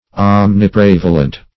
Search Result for " omniprevalent" : The Collaborative International Dictionary of English v.0.48: Omniprevalent \Om`ni*prev"a*lent\, a. [Omni- + prevalent.]